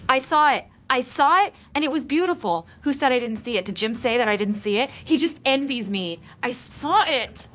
Guess which part is synthesized!